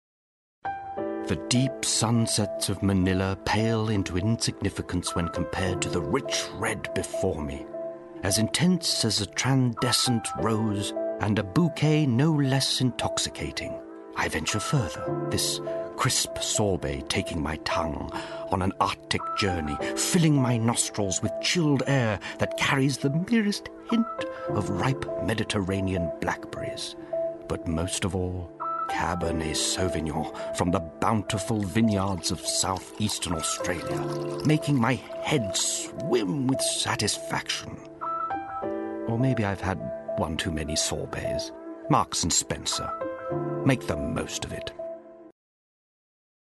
Voice Reel
James Dreyfus - MS - Mellow, Emotional, Humorous
James Dreyfus - MS - Mellow, Emotional, Humorous.mp3